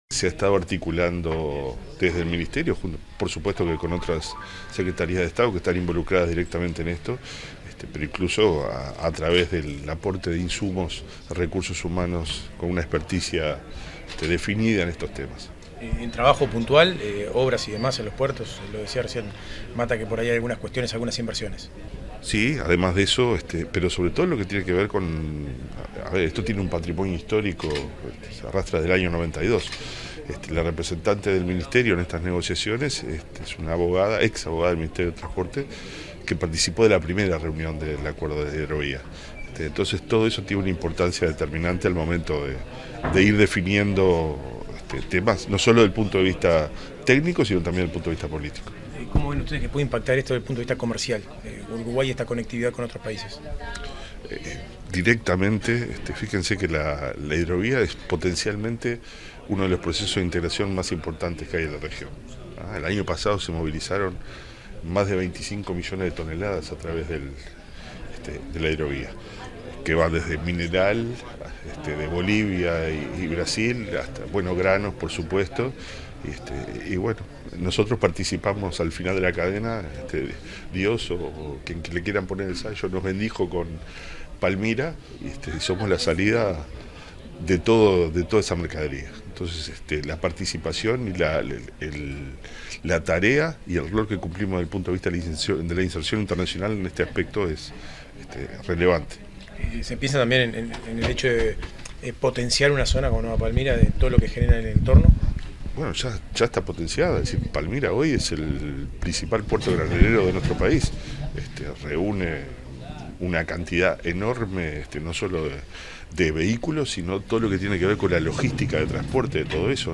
Declaraciones del director nacional de Transporte, Pablo Labandera
Luego de la apertura de la LVIII Reunión de la Comisión del Acuerdo de la Hidrovía Paraguay-Paraná, este 19 de mayo, el director nacional de Transporte, Pablo Labandera, realizó declaraciones a la prensa